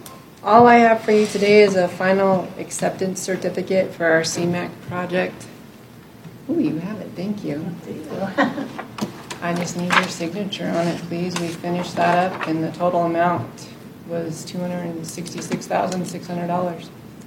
During the June 17th Board of Carbon County Commissioners meeting